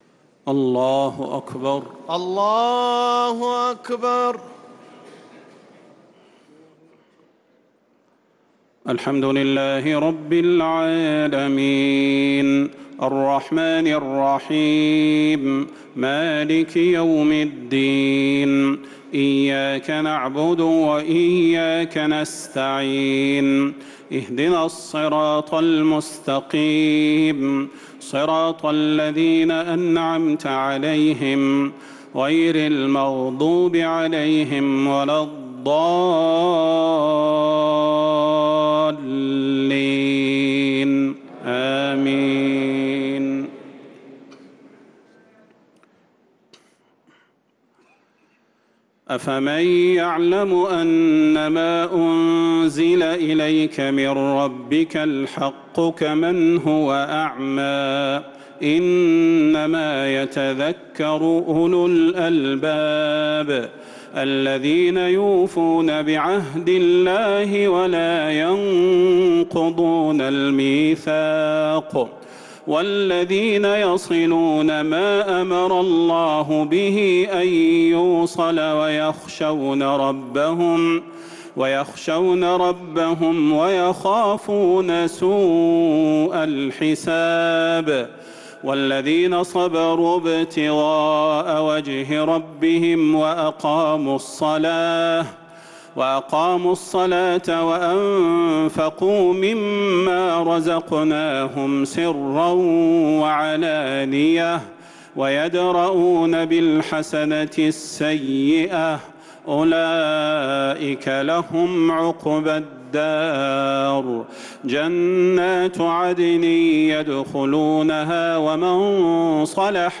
تراويح ليلة 17 رمضان 1446هـ من سورتي الرعد (19-43) و إبراهيم (1-18) | taraweeh 17th night Ramadan 1446H Surah Ar-Ra'd and Ibrahim > تراويح الحرم النبوي عام 1446 🕌 > التراويح - تلاوات الحرمين